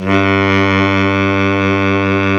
Index of /90_sSampleCDs/Roland L-CDX-03 Disk 1/SAX_Baritone Sax/SAX_40s Baritone
SAX B.SAX 02.wav